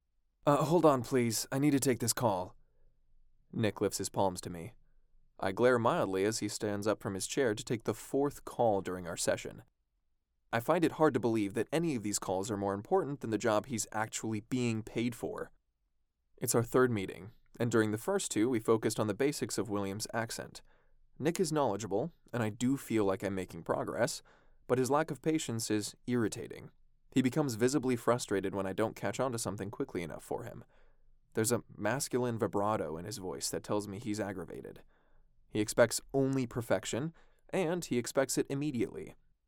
Charismatic male VA with voice smoother than a glass of Pendleton on the rocks.
Audiobook Sample
Audiobook Sample.mp3